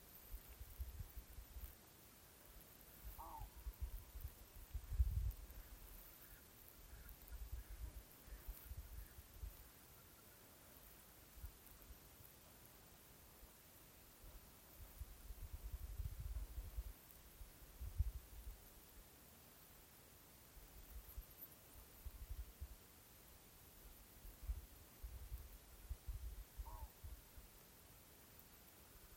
Putns (nenoteikts), Aves sp.
StatussDzirdēta balss, saucieni